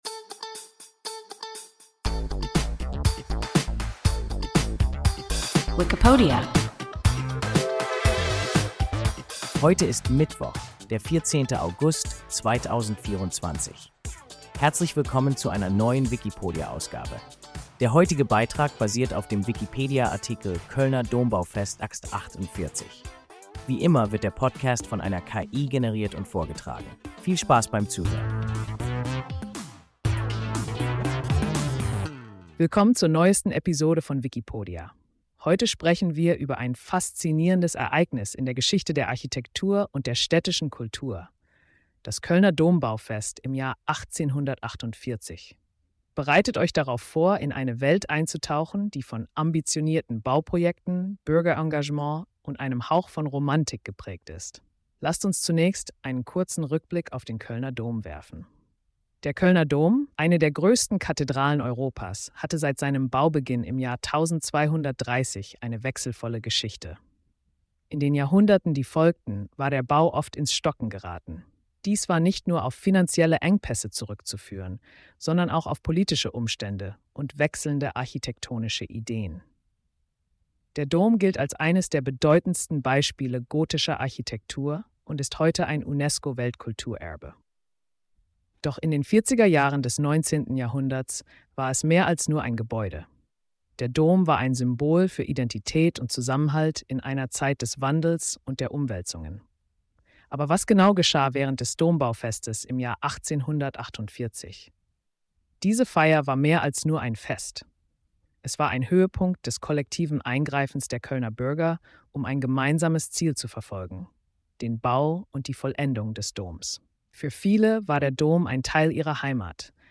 Kölner Dombaufest 1848 – WIKIPODIA – ein KI Podcast